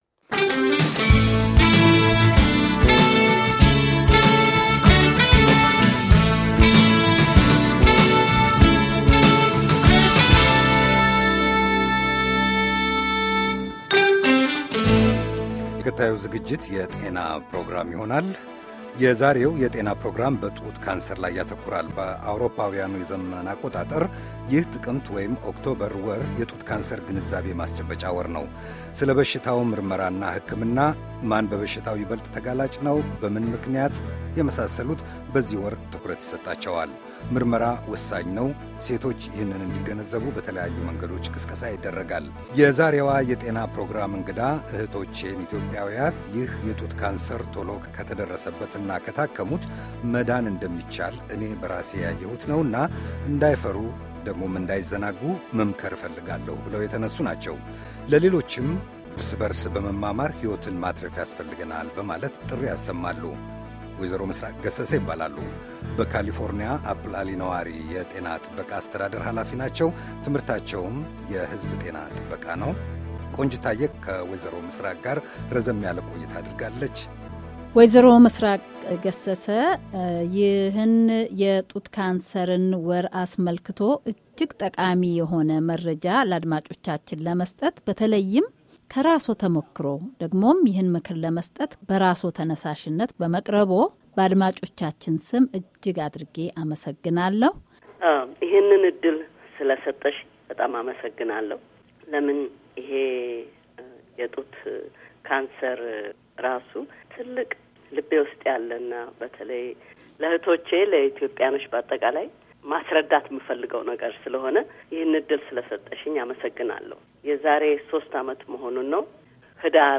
ቃለ ምልልስ
ቃለ መጠይቅ